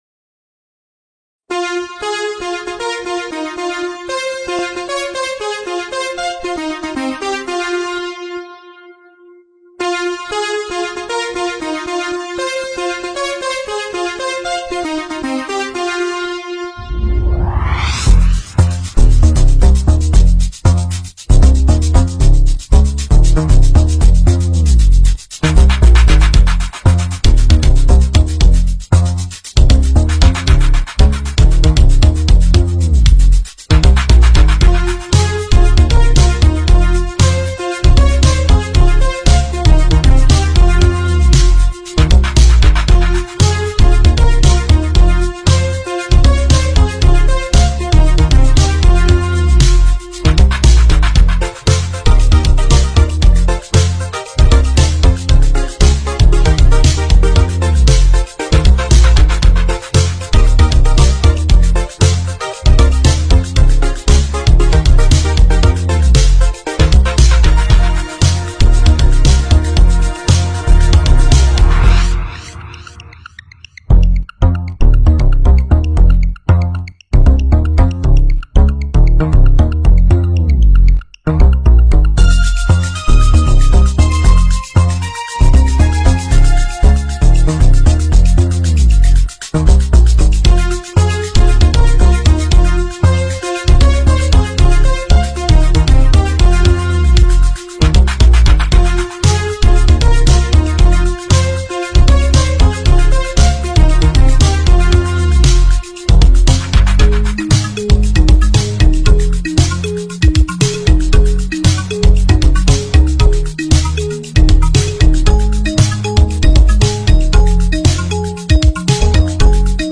Odkąd na Amidze usłyszałem muzyczkę z filmu